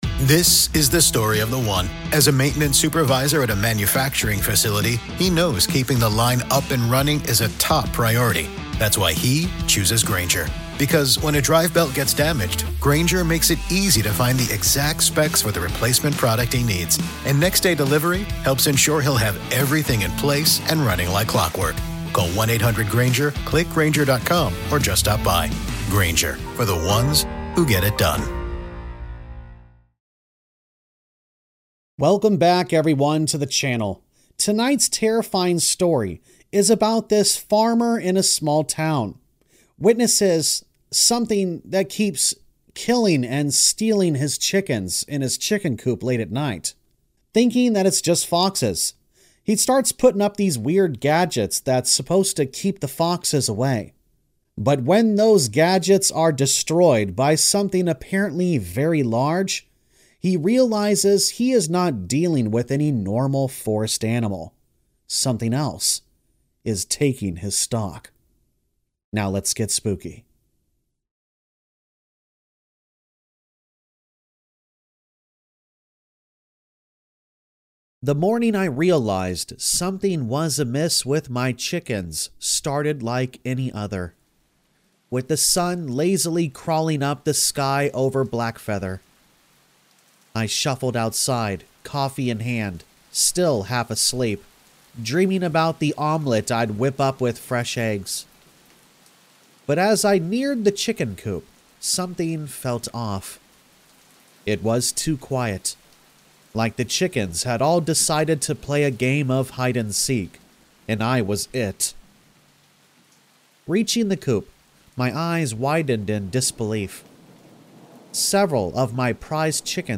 Something's EATING My Goddamn Chickens! | Rain Sounds
All Stories are read with full permission from the authors: